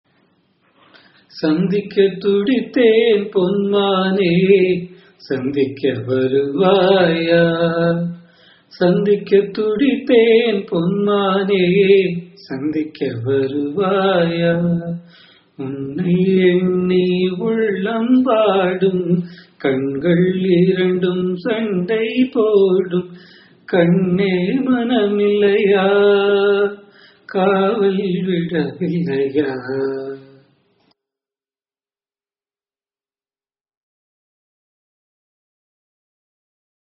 Janyam of 53rd mela Gamanashrama Alias: purvikalyani